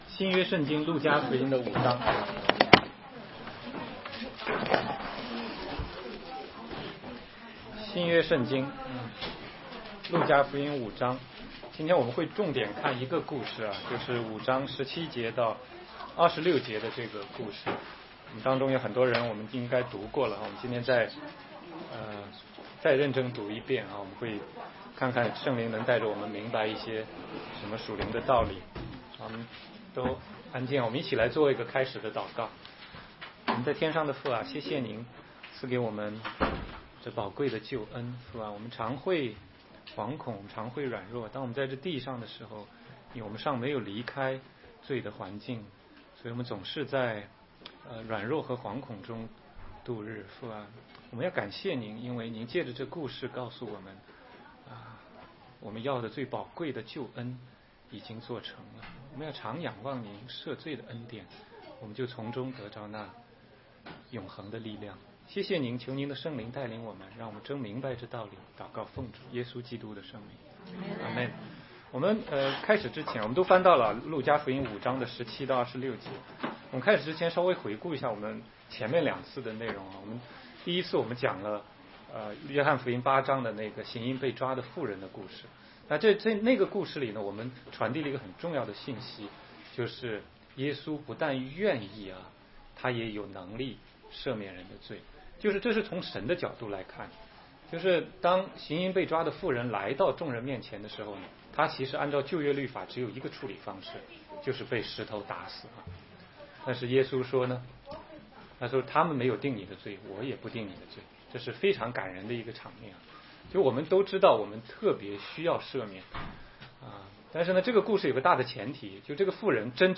16街讲道录音 - 耶稣对瘫子说：“你的罪赦了”